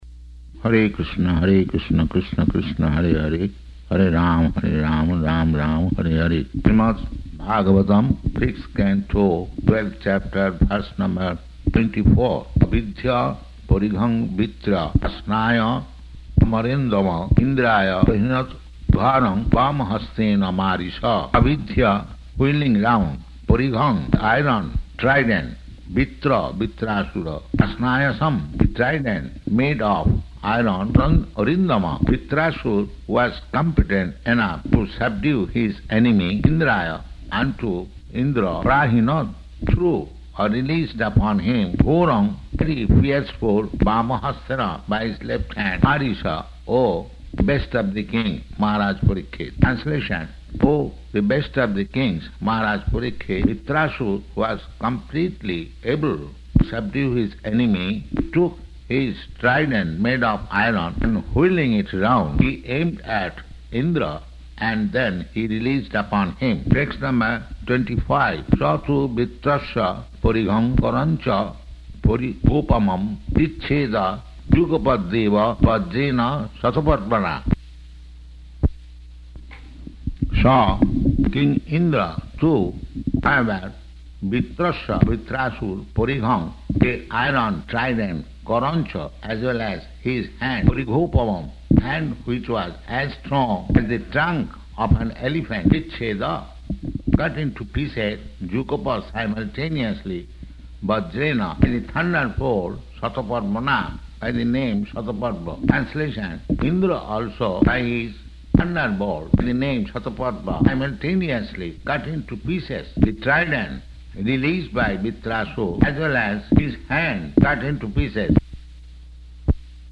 Location: Paris